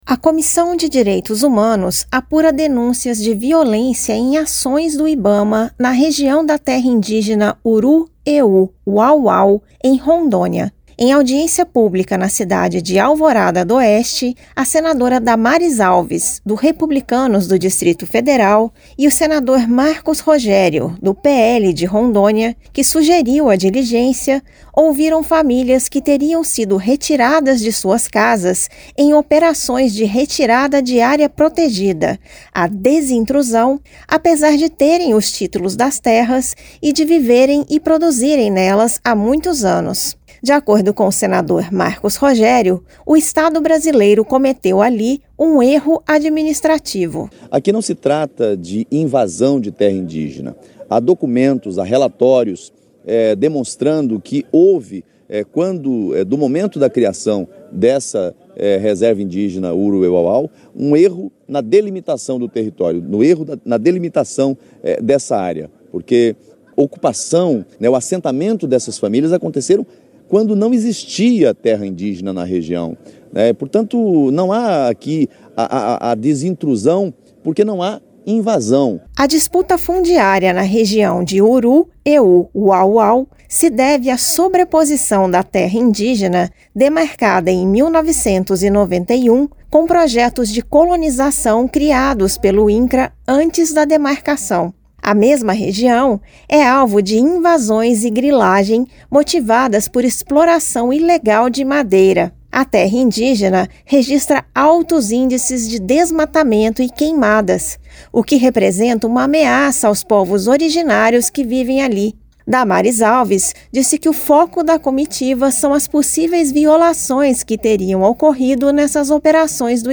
A Comissão de Direitos Humanos fez uma audiência pública na cidade de Alvorada d’Oeste, em Rondônia, com o objetivo de apurar denúncias de violência em ações do Ibama na região da terra indígena Uru-Eu-Wau-Wau.